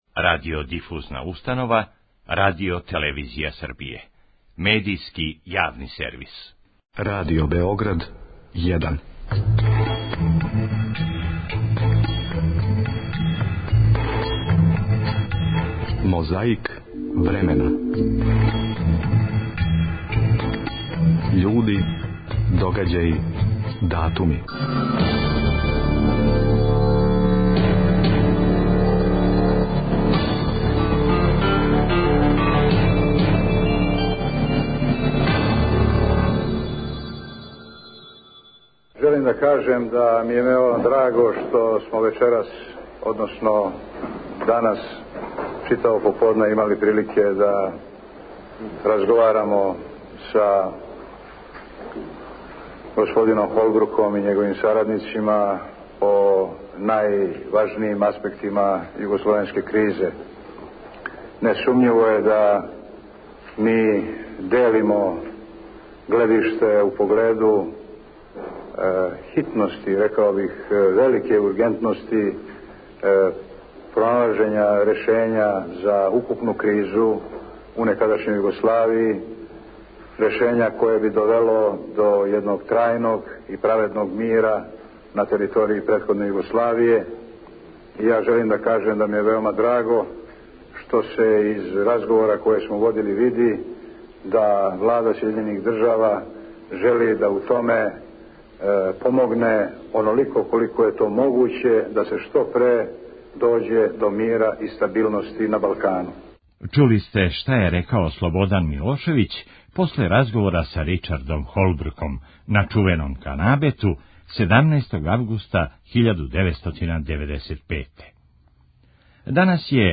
У емисији "Гравитација" БК телевизије, 16. августа 1997. године, у својству кандидата за председника Србије, гост је био Војислав Шешељ.
Протести који су коначно помогли Милошевићу да дође на власт почели су у Србији те године, а ми слушамо белешку са протеста из Нове Пазове.